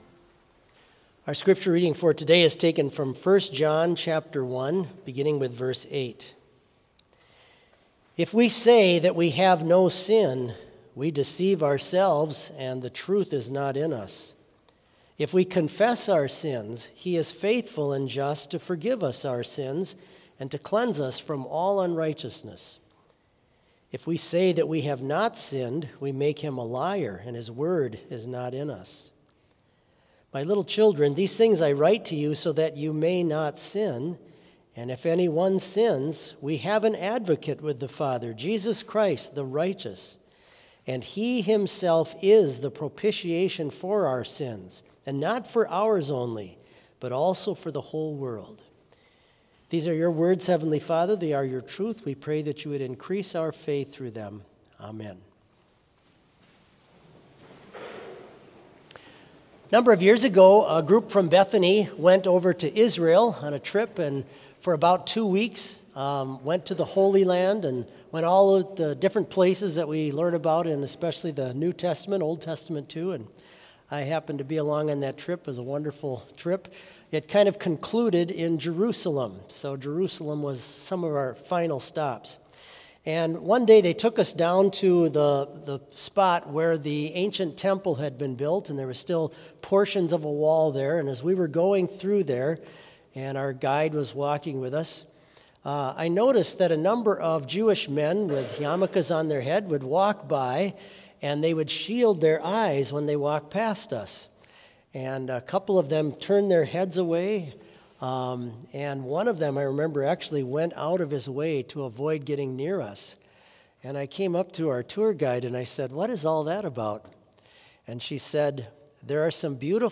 Complete Service
• Hymn 302 - The Tree of Life With Ev'ry Good View
This Chapel Service was held in Trinity Chapel at Bethany Lutheran College on Wednesday, April 5, 2023, at 10 a.m. Page and hymn numbers are from the Evangelical Lutheran Hymnary.